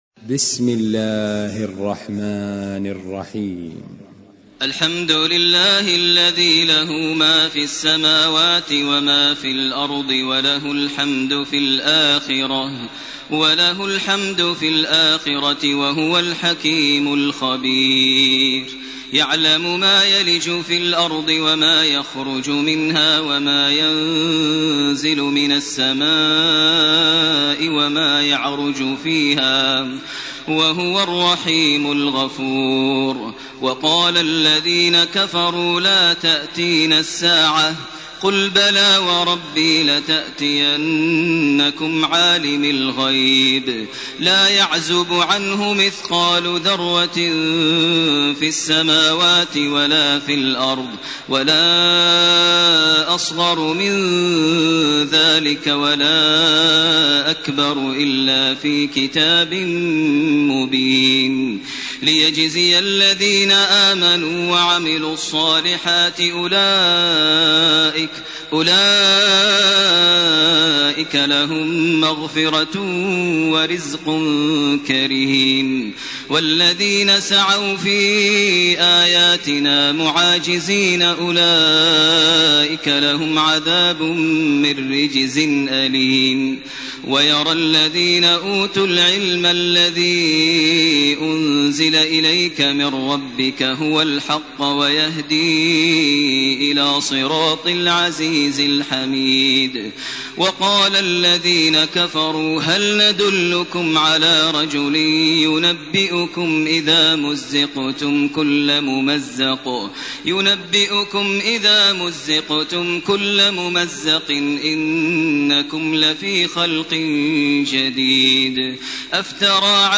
سورة سبأ وسورة فاطر > تراويح ١٤٢٨ > التراويح - تلاوات ماهر المعيقلي